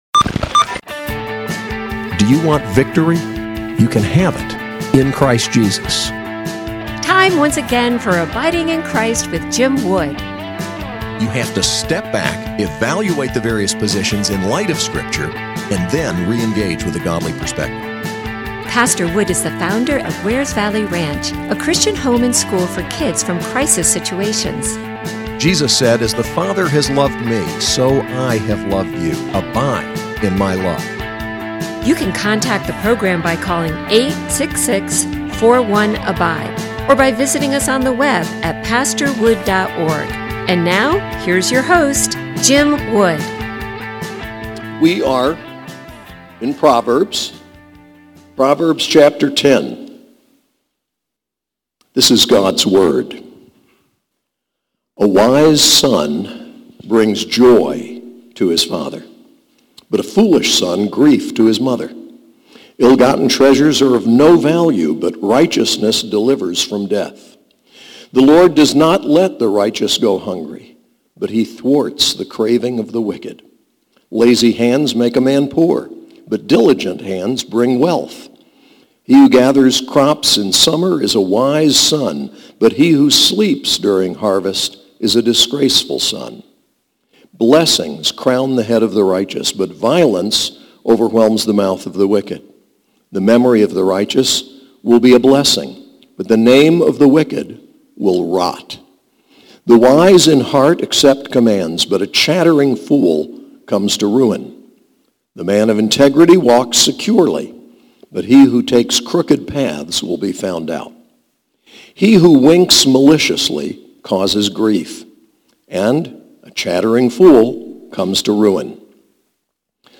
SAS Chapel: Proverbs 10